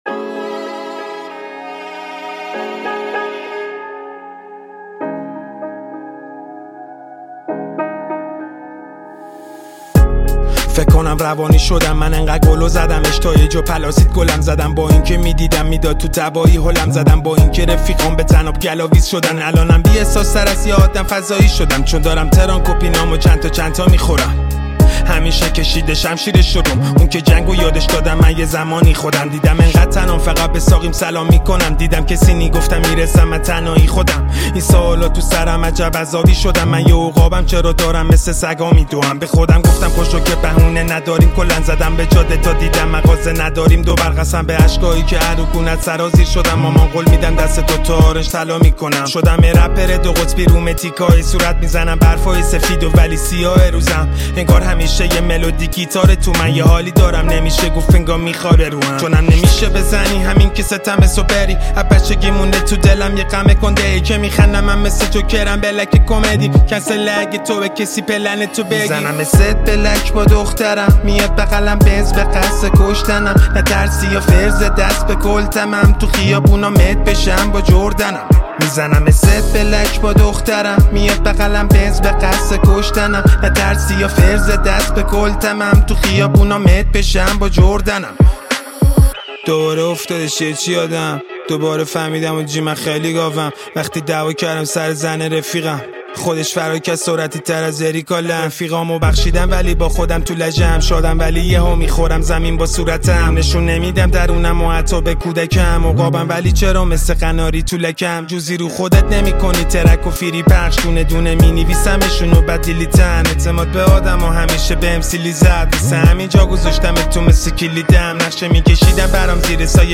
تک آهنگ